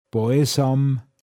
pinzgauer mundart
Båesåm, m. Balsam